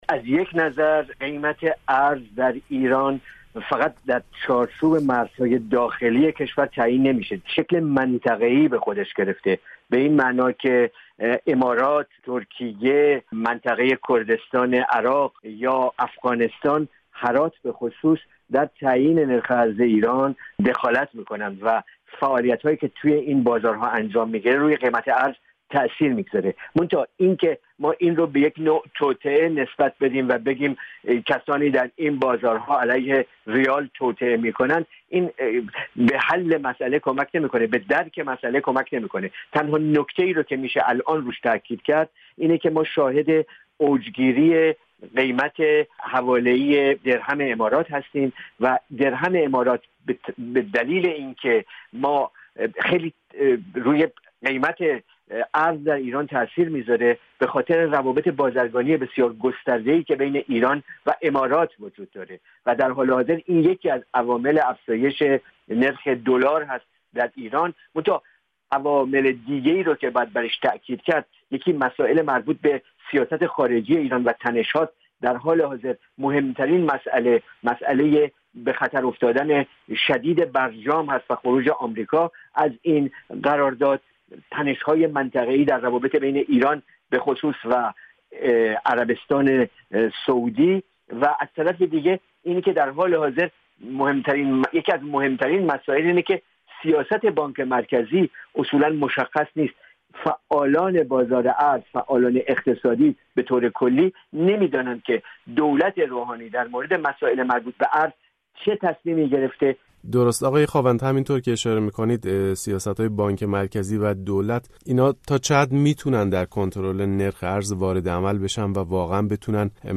در گفت و گویی با رادیو فردا